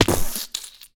fallbig.ogg